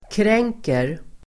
Uttal: [kr'eng:ker]